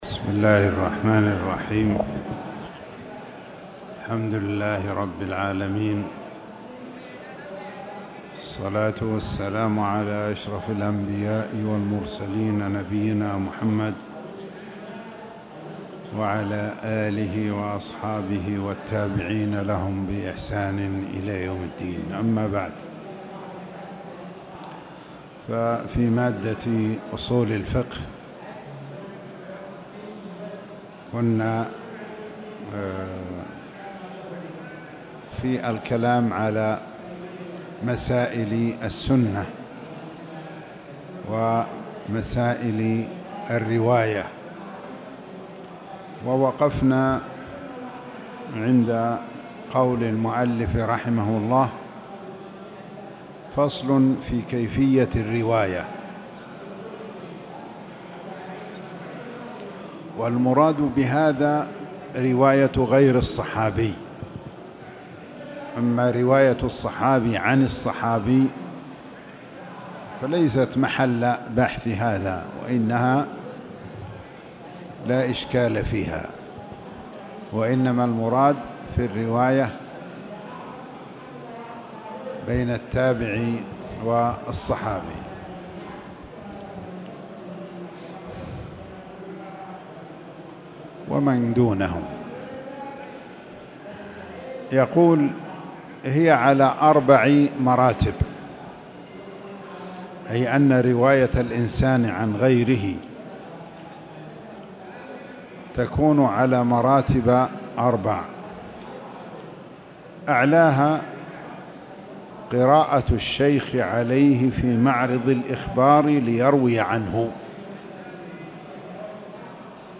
تاريخ النشر ٣ محرم ١٤٣٨ هـ المكان: المسجد الحرام الشيخ